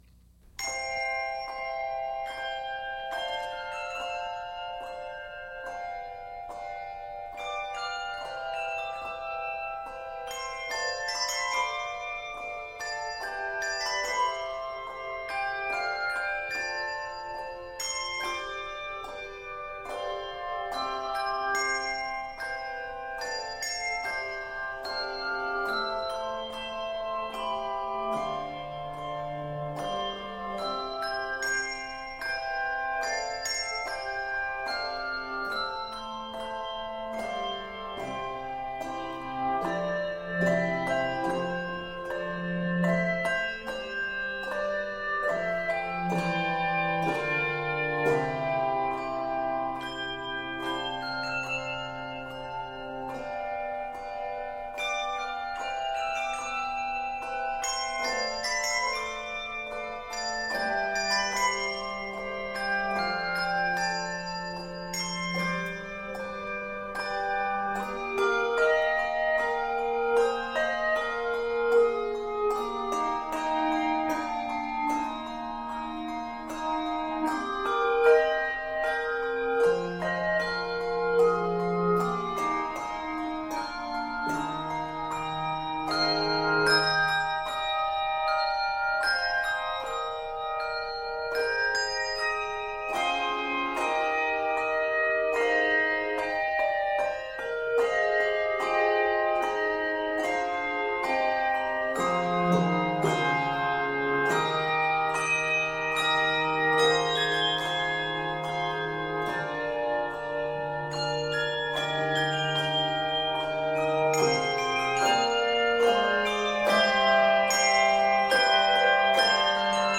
It is set in C Major and Eb Major.